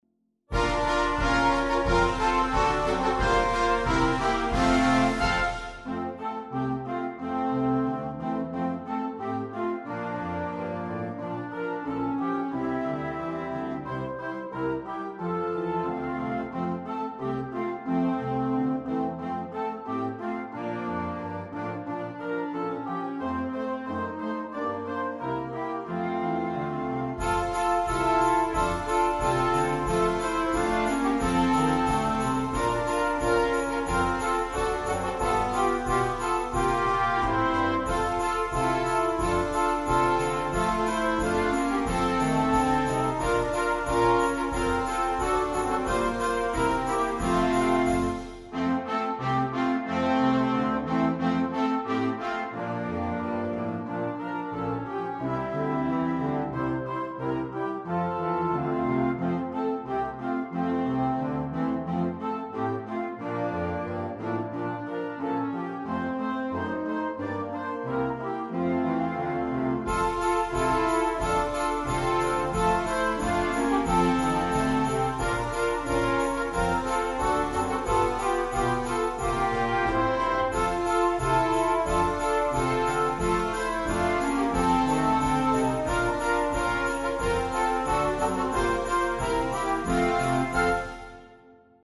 MUSICA PER BANDA
a 4 voci